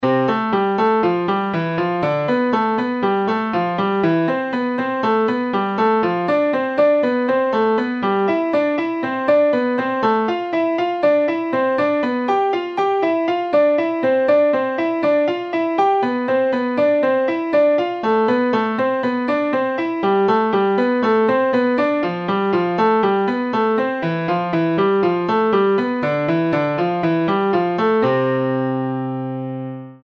La musique jouée par un logiciel est à titre indicatif.
Facile à mémoriser car c’est chaque fois une cellule de une mesure qu’on rejoue une touche plus haute pour la montée et une touche plus basse pour la descente.
hanon_ex05.mp3